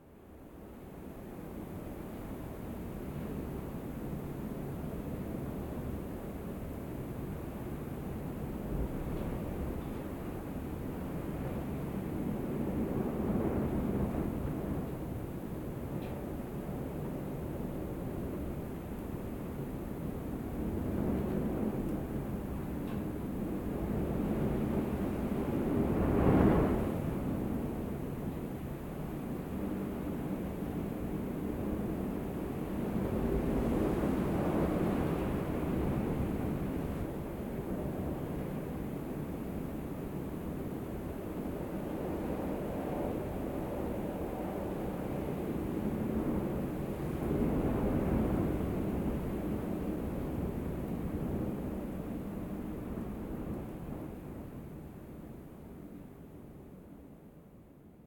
wind-sound.ogg